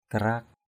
/ka-ra:k/ (d.) giời ăn (vẩy nến) = psoriasis. psoriasis.